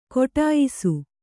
♪ koṭāyisu